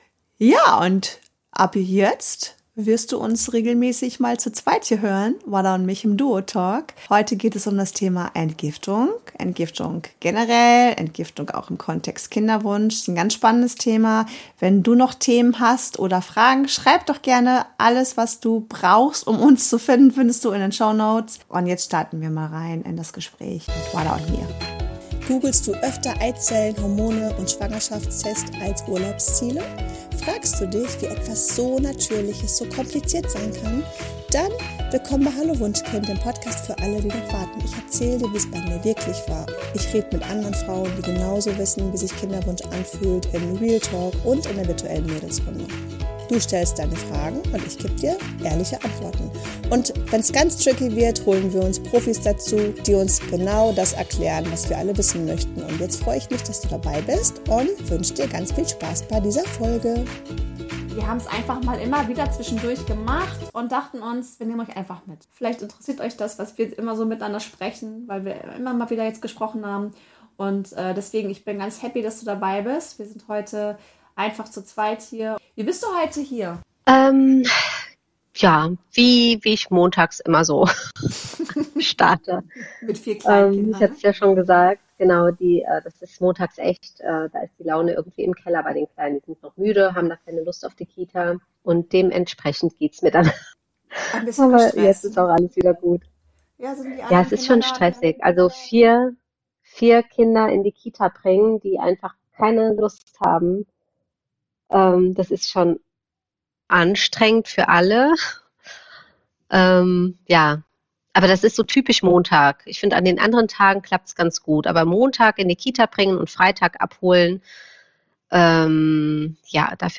Worauf kann man auch im Alltag achten, um Körper und Seele zu entlasten? Ein tiefgehendes, ehrliches Gespräch über Erfahrung, Wissen und Vertrauen in den eigenen Körper.